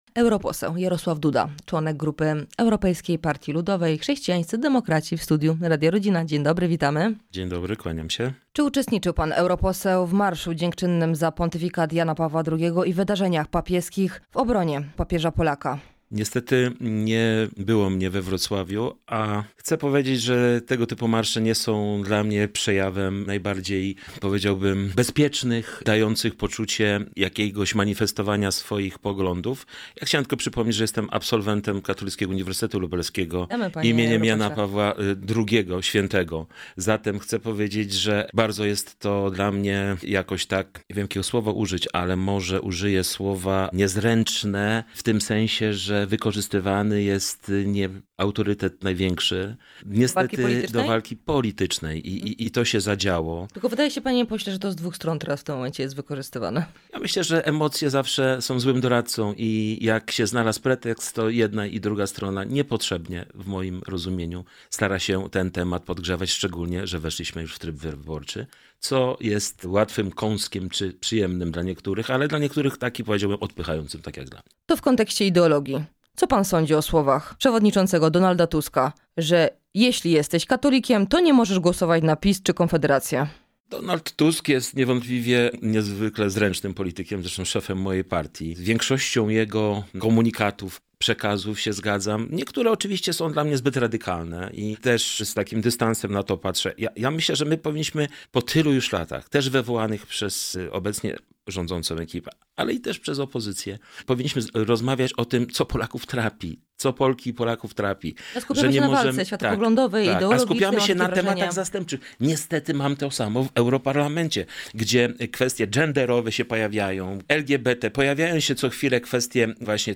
O marszu, kampanii wyborczej czy propozycjach Donalda Tuska rozmawiamy z europosłem Jarosławem Dudą.
PG_Jaroslaw-Duda_europosel-PJB.mp3